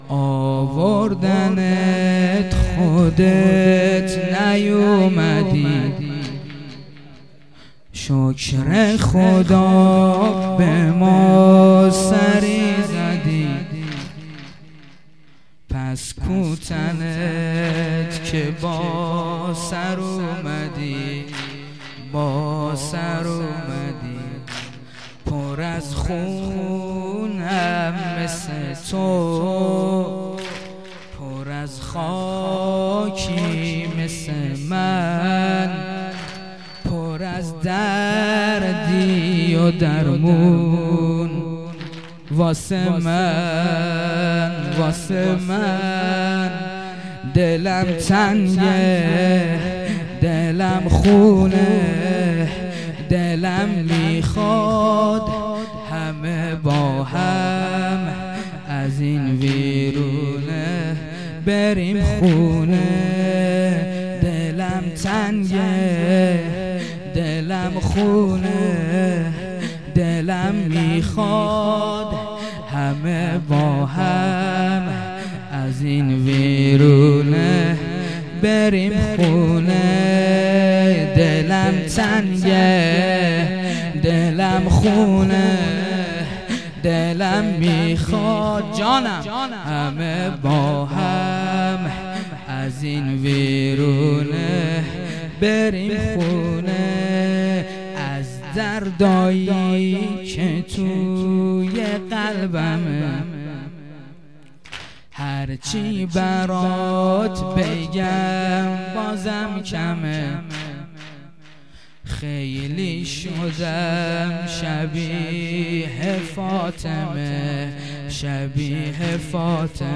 خیمه گاه - هیئت قتیل العبرات - مداحی
شب سوم محرم